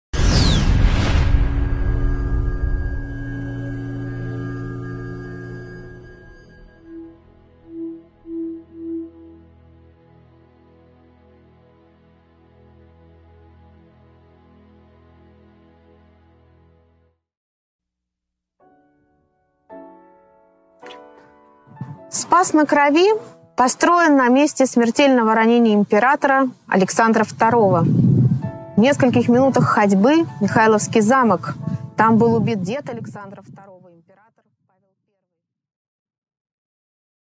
Аудиокнига Теплое самодержавие. Александр III. Эпизод 3 | Библиотека аудиокниг